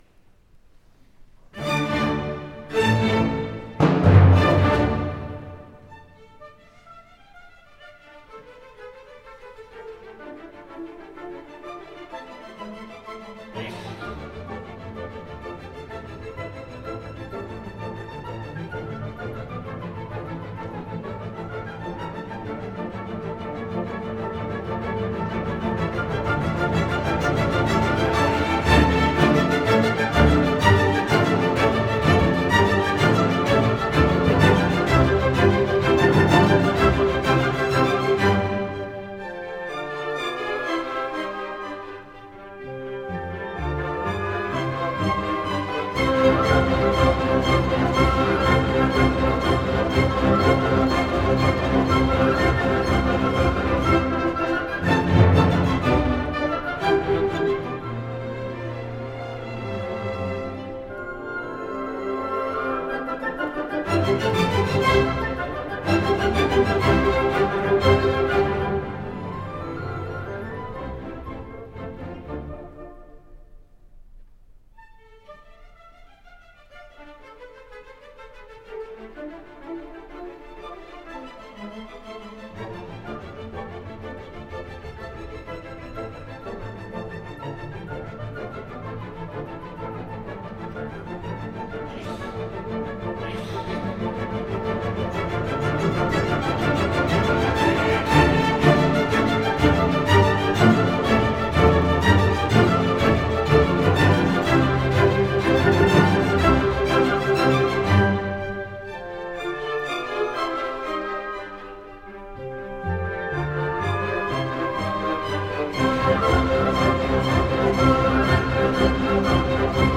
soprano
mezzo-soprano
tenor
baryton Orchestre national des pays de la Loire
conductor